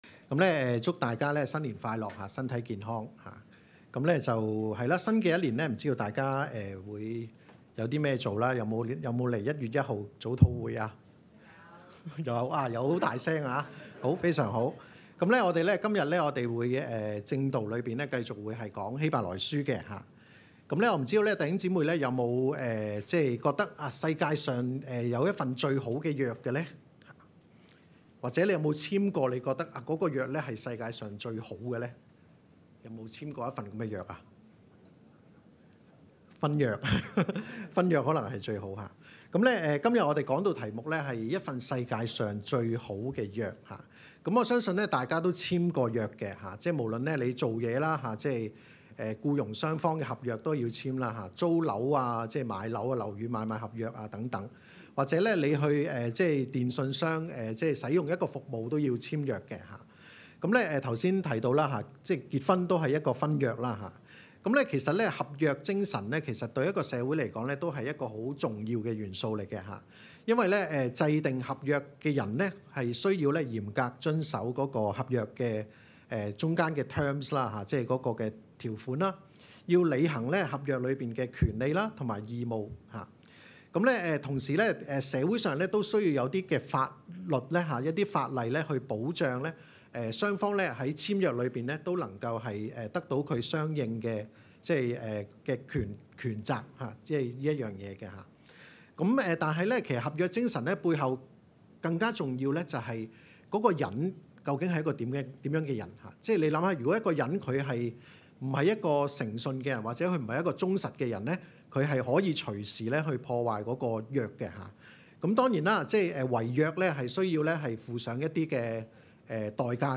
证道集